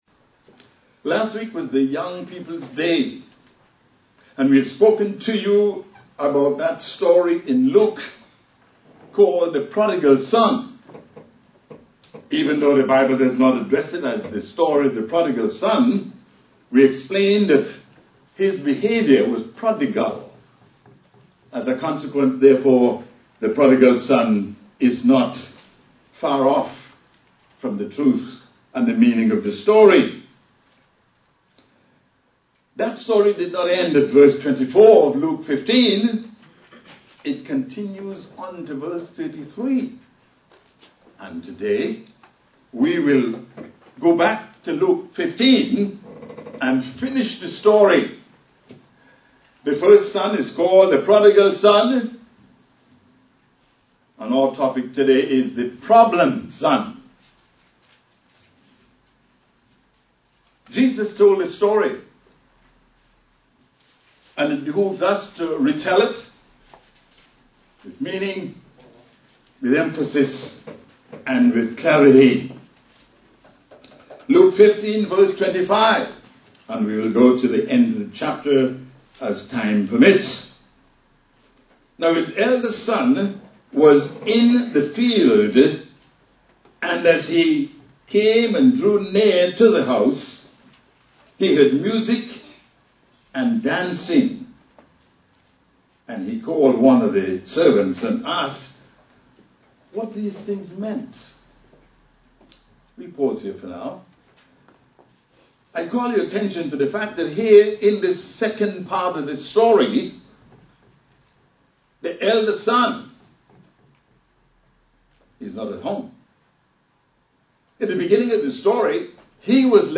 Print UCG Sermon Studying the bible?